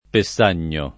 [ pe SS# n’n’o ]